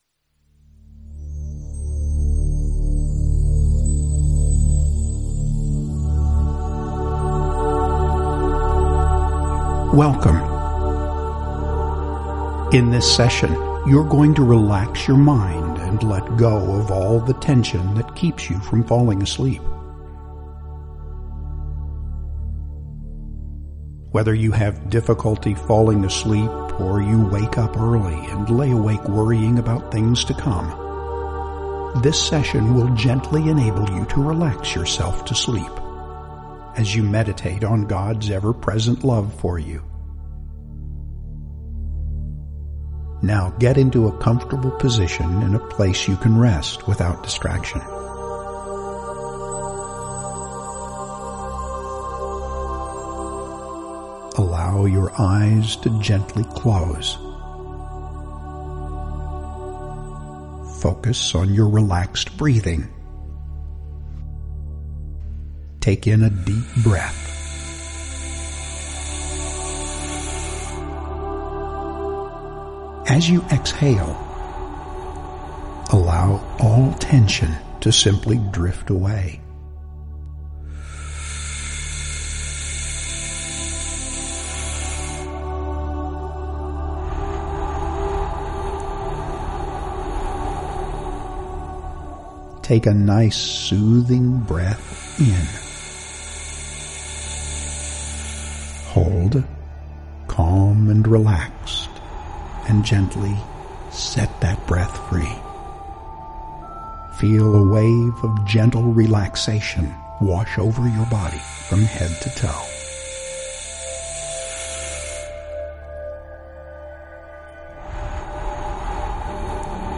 Tonight when you are ready for bed, pop in your earbuds, click on the audio clip below and relax as you close your eyes and listen to a small portion of our “Beside Quiet Waters” sleep session that will reinforce today's devotional as you drift off to sleep. NOTE: Sleep sessions begin with relaxation techniques that prepare you for sleep, a spiritually encouraging message that reinforces today’s topic and then final relaxation techniques to help you sleep peacefully tonight.